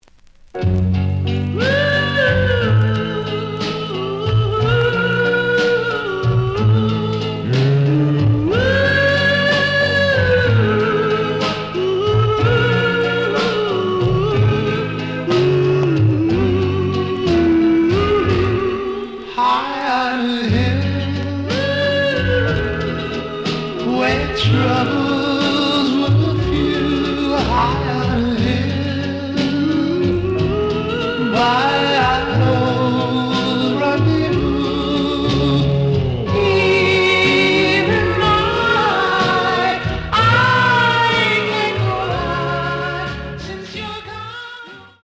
●Genre: Rock / Pop
Slight edge warp.